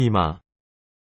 韓国語で「おでこ」は「이마（イマ）」といいます。